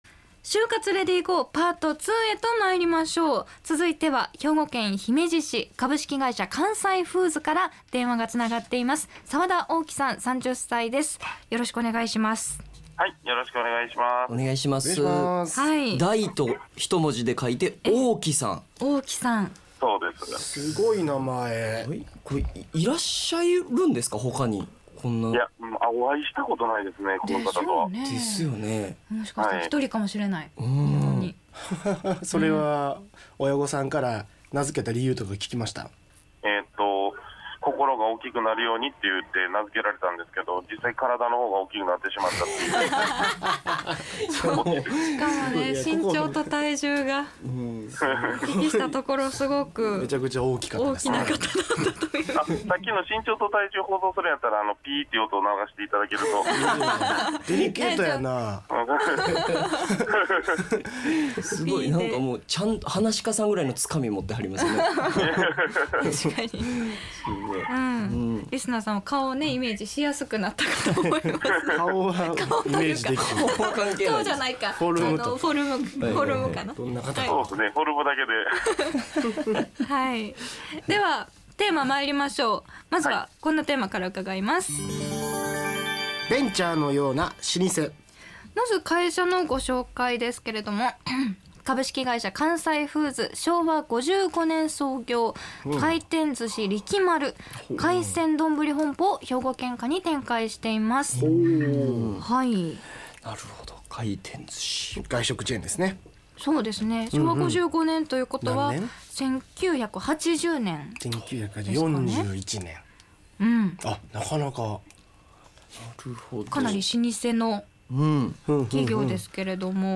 社会人として活躍する先輩たちは、いったいどんな就職活動を経験し、今日に至るのか。先輩社会人ロールモデルが、ラジオ番組でその実体験を語った。